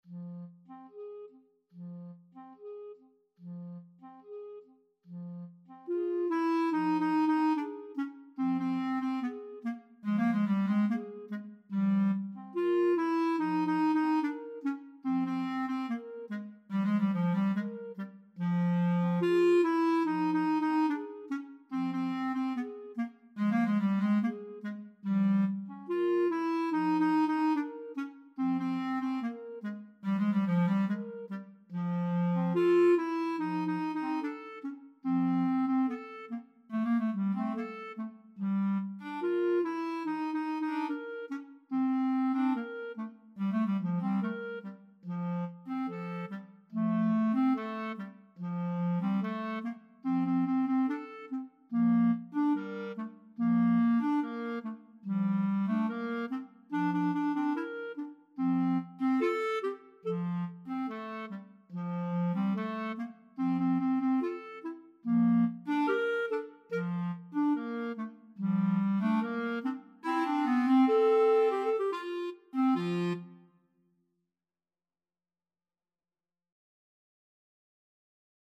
2/4 (View more 2/4 Music)
Classical (View more Classical Clarinet Duet Music)